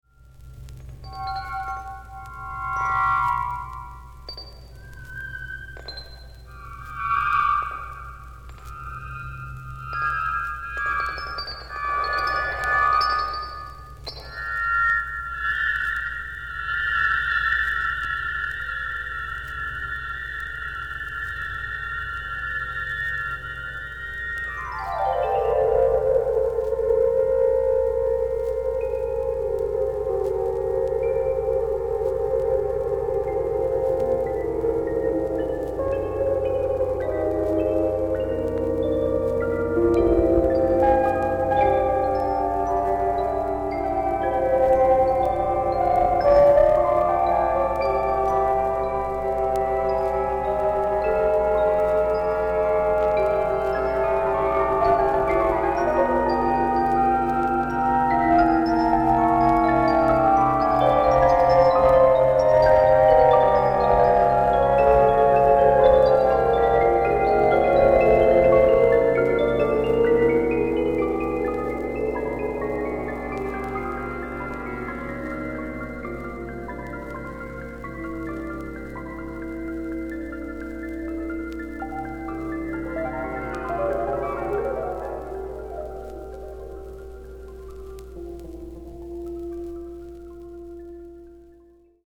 悪魔的に冴えわたる指づかい、空間を厚く覆うアブストラクトな陰影の質感も相まって、魔界味あふれる際どい仕上がり。
キーワード：ミニマル　室内楽　地球外　即興